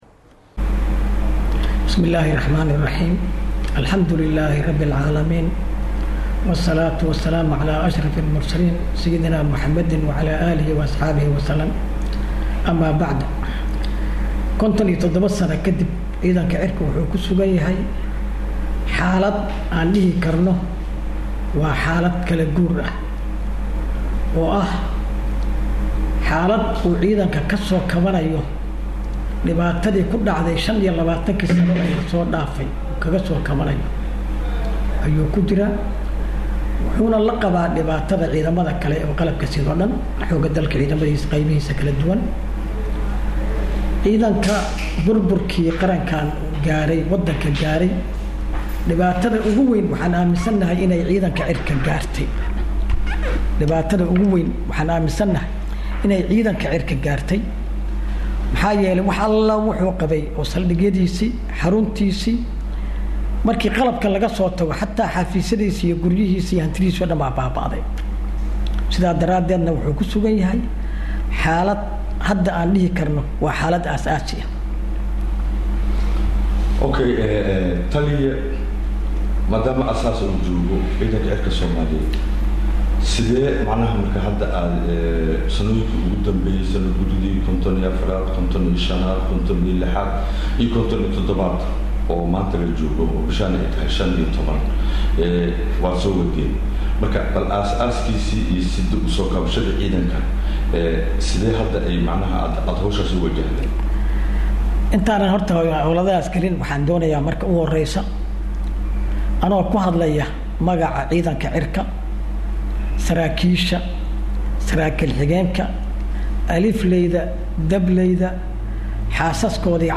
Halkan ka dhageyso Wareysiga Taliyaha ciidanka
Taliyaha-ciidamada-cirka-Soomaaliyeed-Sareeyo-Guuto-Maxamuud-Sheekh-Cali-Dable-beylood-.mp3